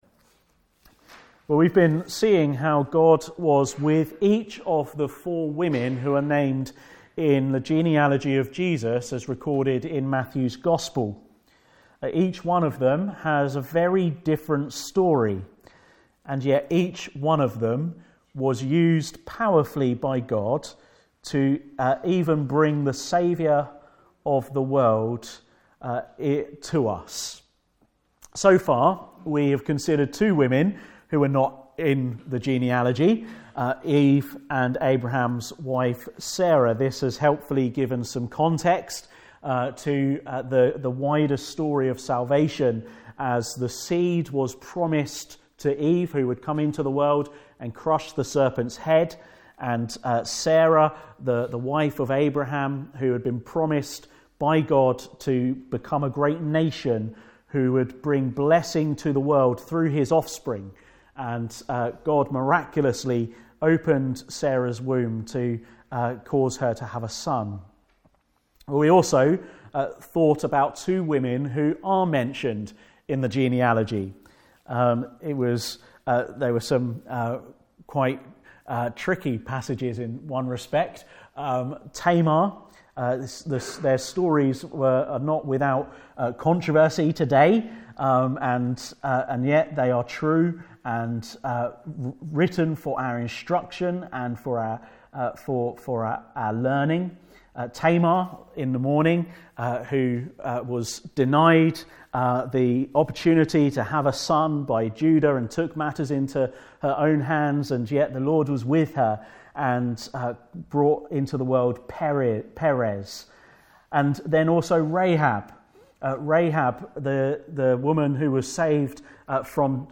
God with Us Passage: Ruth 1: 1 - 22 Service Type: Sunday Morning « God with the Fearful